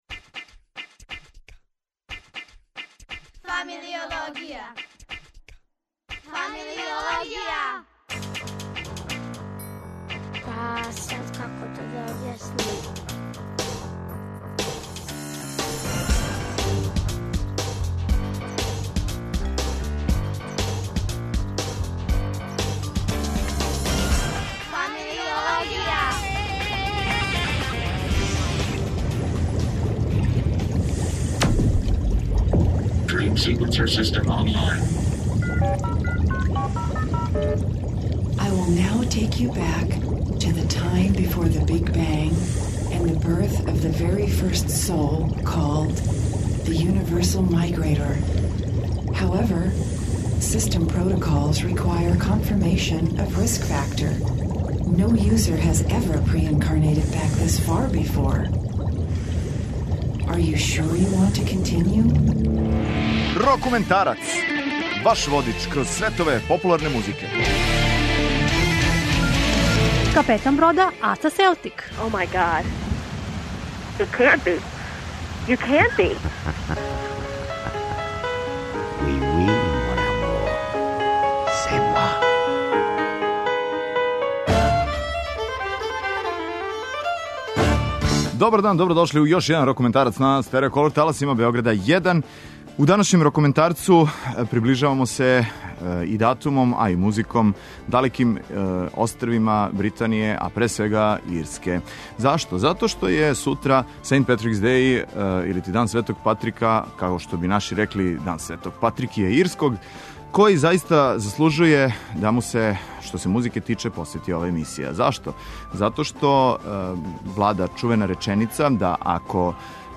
Сутра је 17. март, познатији као St. Partick`s Day. Поводом овог празника слушамо најбоље и највеселије ирске песме.